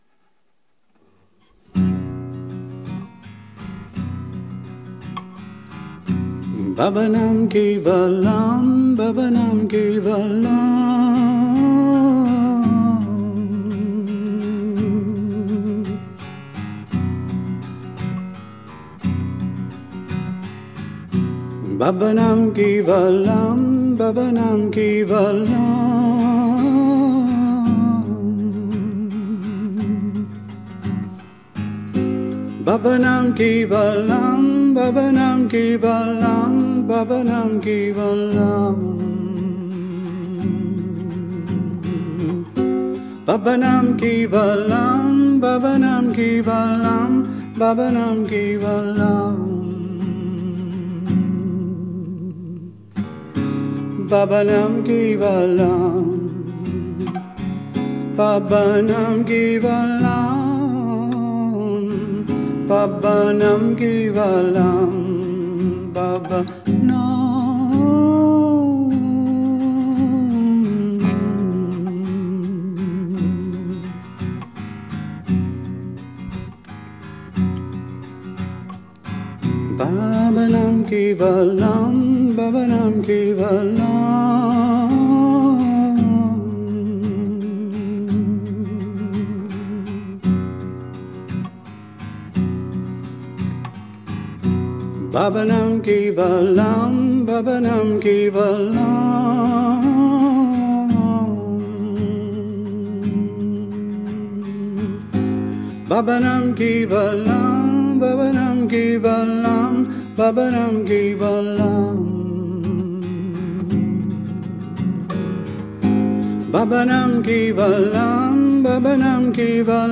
guitar and voice